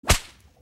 Звуки удара ремнём
Хороший шлепок